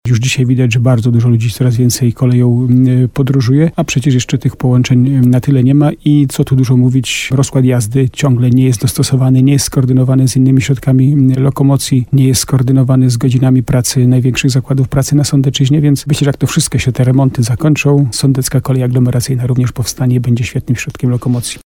Burmistrz Starego Sącza Jacek Lelek, podkreśla, że mieszkańcy coraz bardziej przekonują się do kolei.